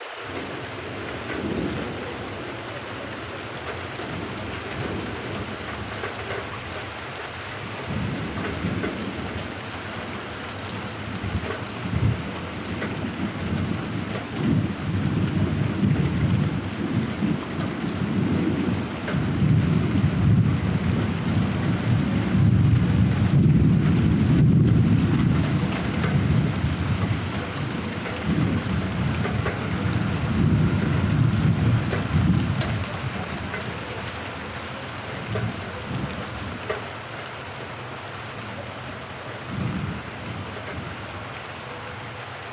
Tormenta con lluvia